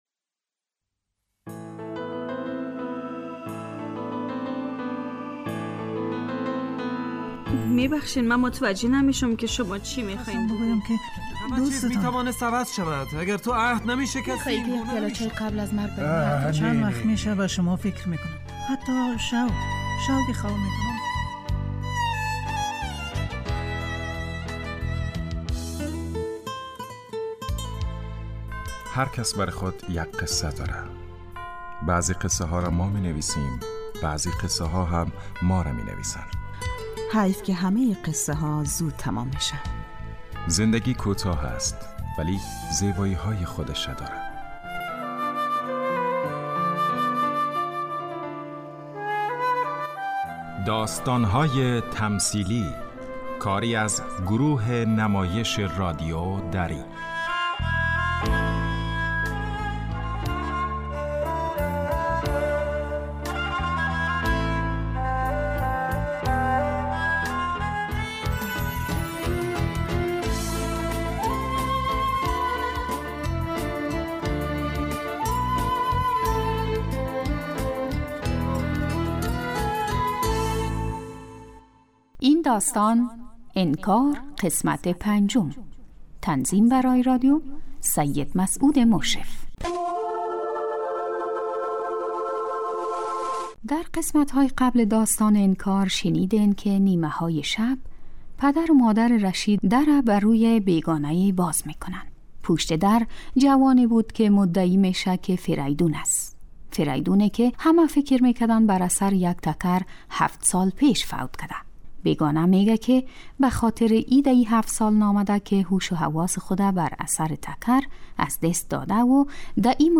داستانهای تمثیلی نمایش 15 دقیقه ای هستند که هر روز ساعت 3:30 عصربه وقت وافغانستان پخش می شود.